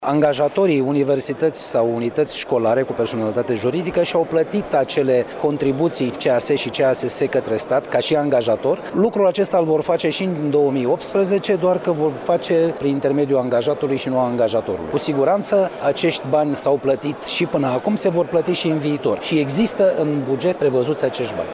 La rândul său, secretarul de stat din Ministerul Educaţiei , Gigel Paraschiv, a precizat că salariaţii din învăţământ nu trebuie să aibă motive de îngrijorare legate de transferul contribuţiilor de la angajator la angajat:
Ministrul Educaţiei, Liviu Pop, şi secretarul de stat, Gigel Paraschiv, au participat, astăzi, la întâlnirea Consiliului Naţional al Rectorilor care s-a desfăşurat la Iaşi.